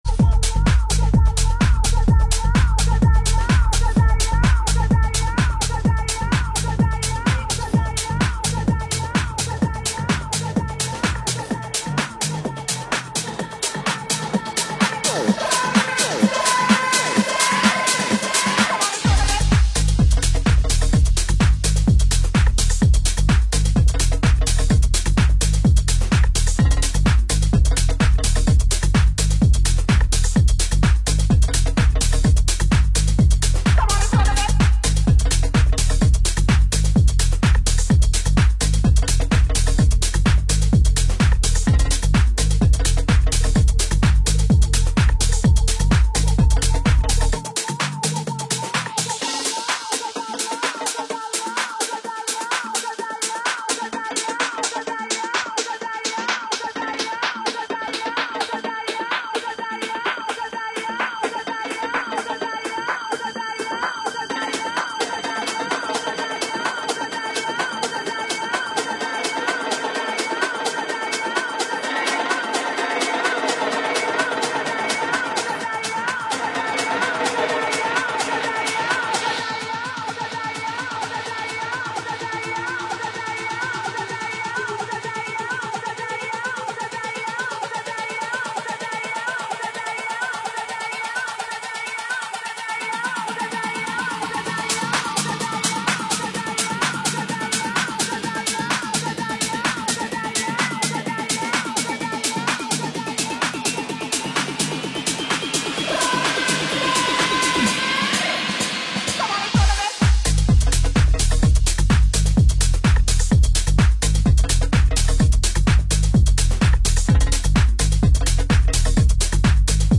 liveset